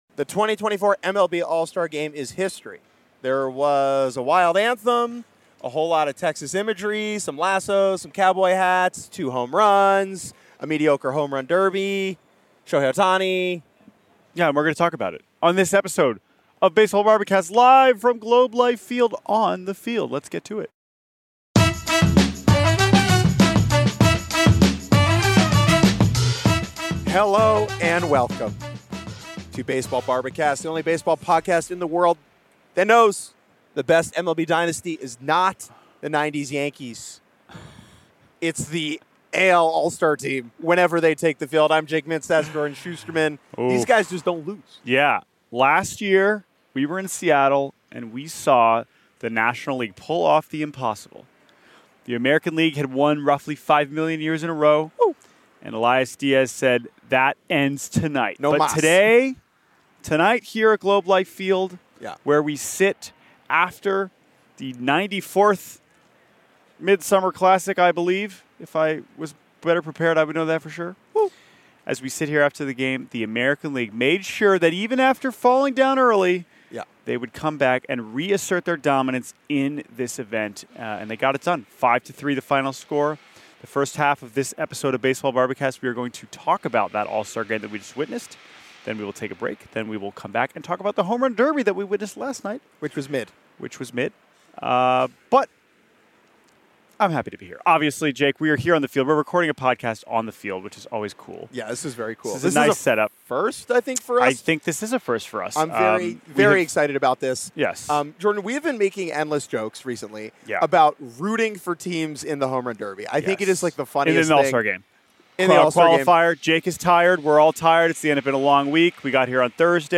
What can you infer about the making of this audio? Recording live on the field from Texas - Memorable moments from the All-Star Game